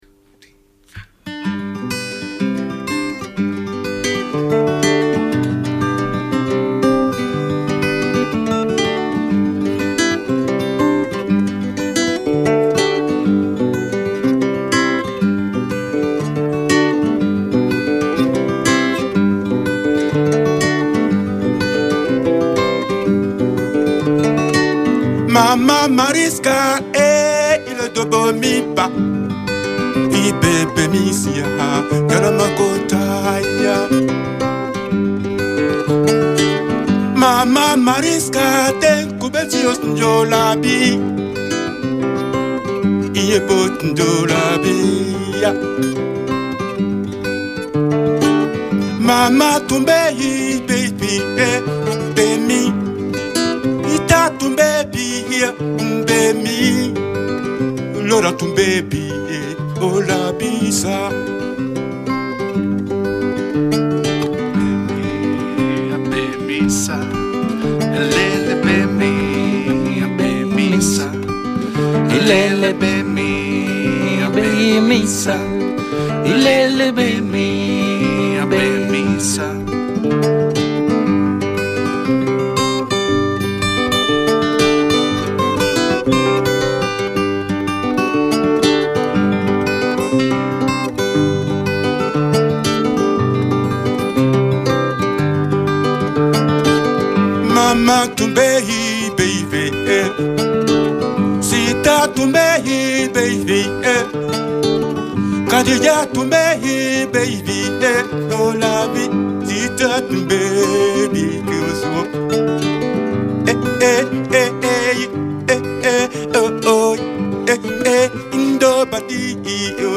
played live on the Dutch radio